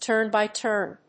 turn-by-turn.mp3